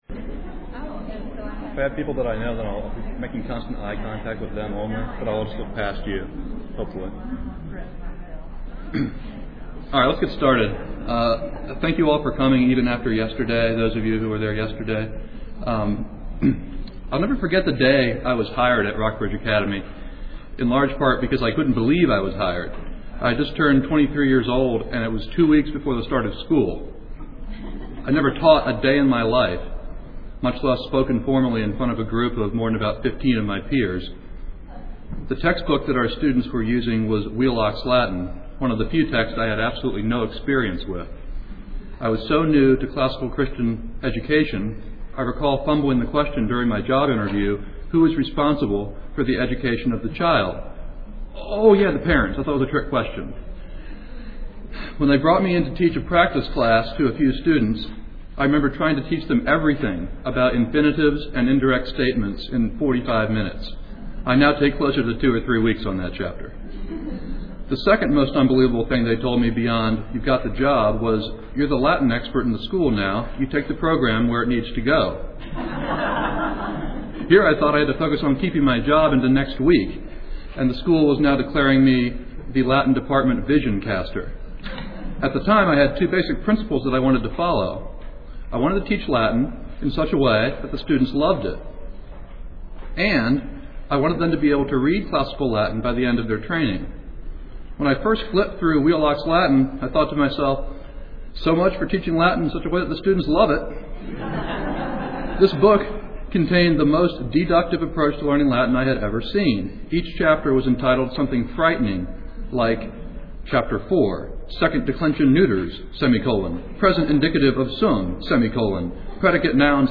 2007 Workshop Talk | 0:53:10 | All Grade Levels, Latin, Greek & Language
The Association of Classical & Christian Schools presents Repairing the Ruins, the ACCS annual conference, copyright ACCS.